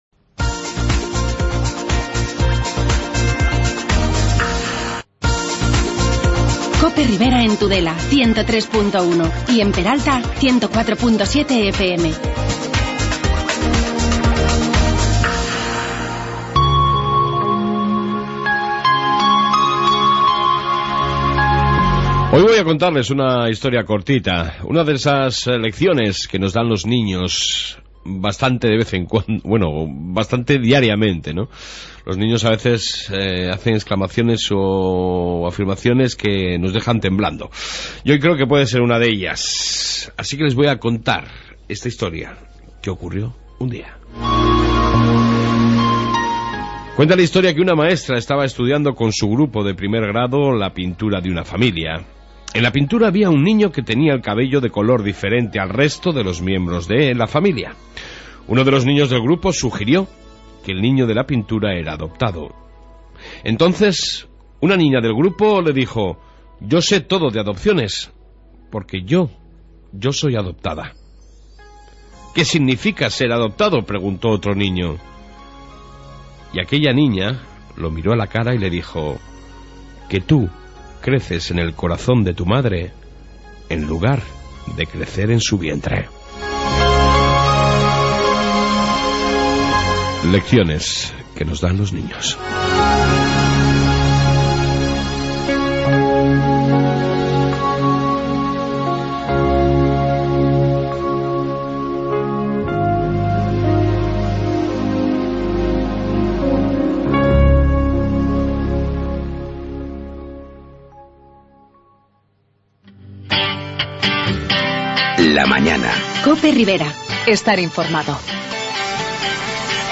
AUDIO: En esta 1 parte noticias Riberas y entrevista con el Alcalde de Arguedas Fernando Mendoza